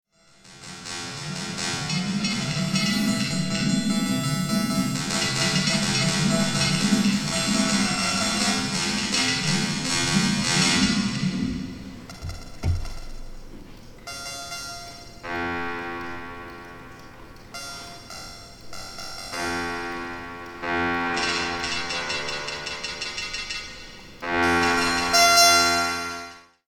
Suddenly, the players will all have the same note.
All of the players will suddenly sound staccato.